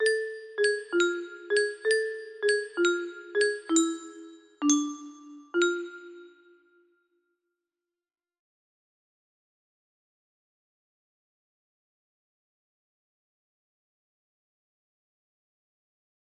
test melody music box melody